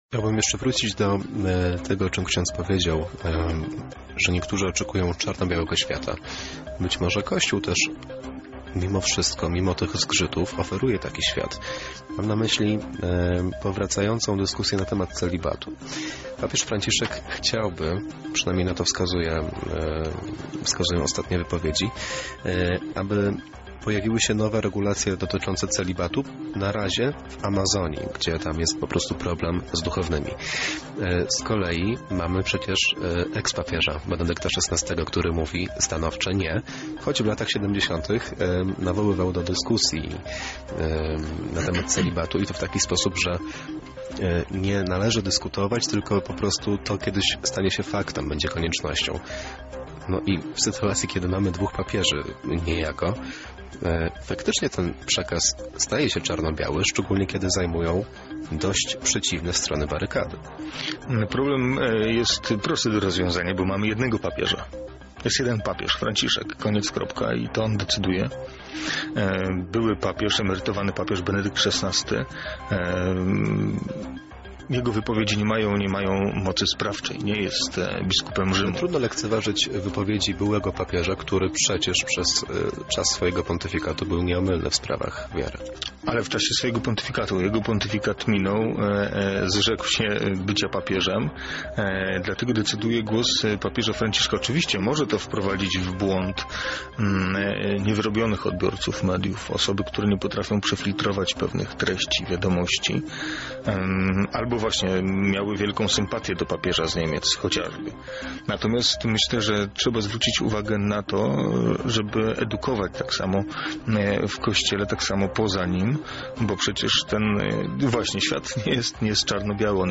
Pełna rozmowa dostępna poniżej. Usłyszycie w niej więcej na temat lekcji religii w szkołach, a także o dyskusji nad celibatem w Kościele Katolickim.